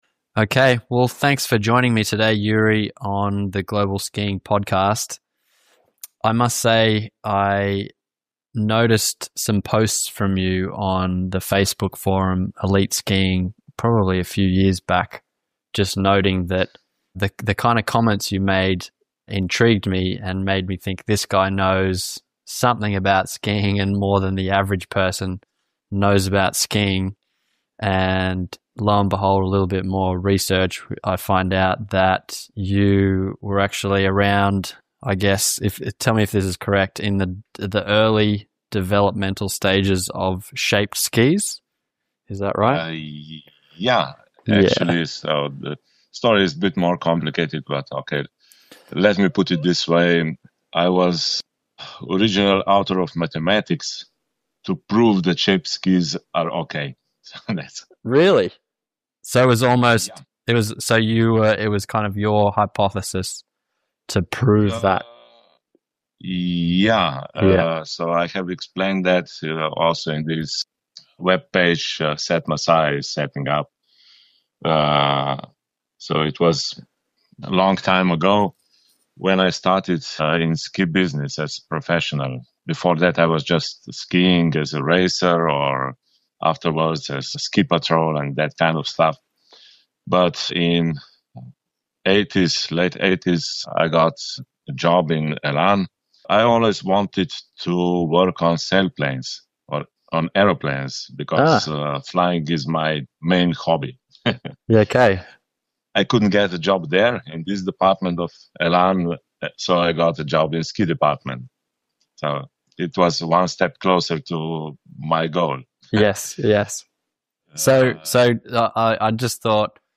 This is a remastered release (2018) of one of the most eye-opening conversations I’ve ever had about skiing.
The audio is now cleaned up, and I’m thrilled to bring this episode back because it genuinely changed how I understand skis.